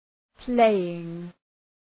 Προφορά
{‘pleııŋ}